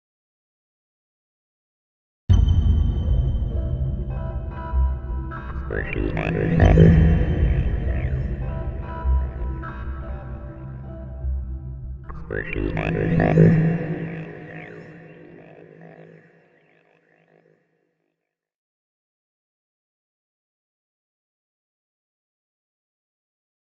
دانلود آهنگ آدم فضایی 1 از افکت صوتی انسان و موجودات زنده
جلوه های صوتی
دانلود صدای آدم فضایی 1 از ساعد نیوز با لینک مستقیم و کیفیت بالا